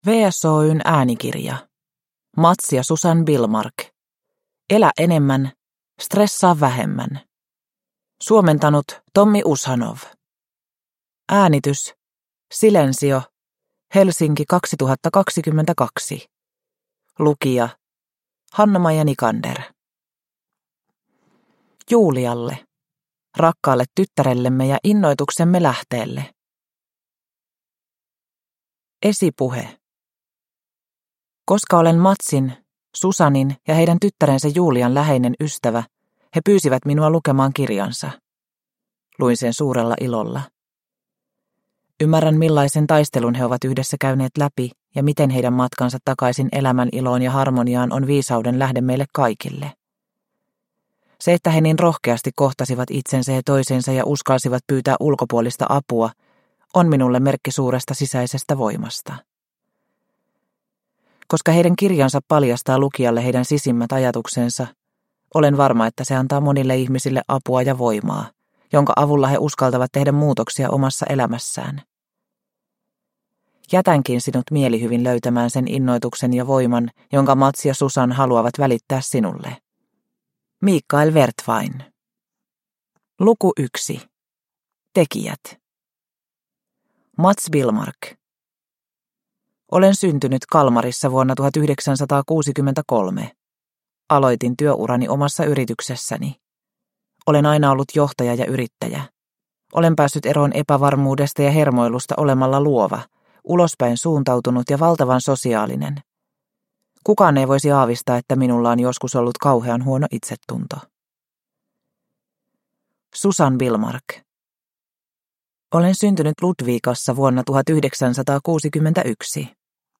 Elä enemmän, stressaa vähemmän – Ljudbok – Laddas ner